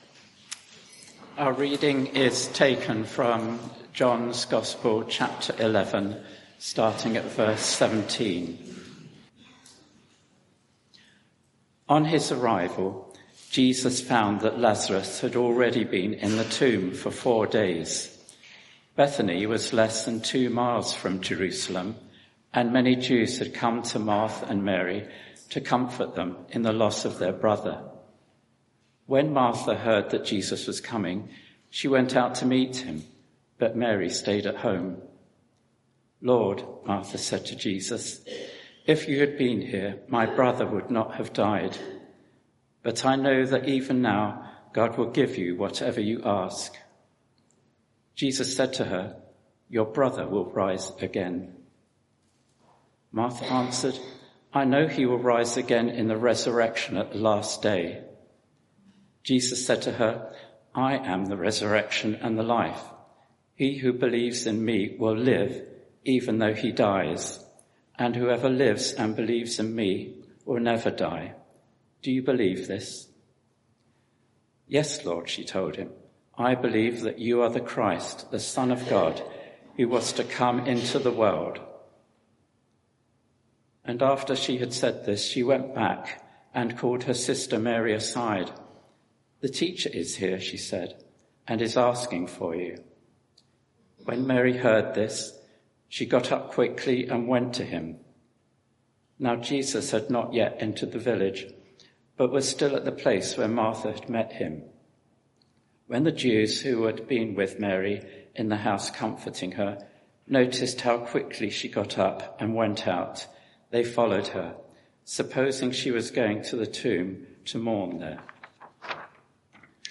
Media for 11am Service on Sun 18th Aug 2024 11:00 Speaker
I AM Theme: I am the resurrection and the life Sermon (audio)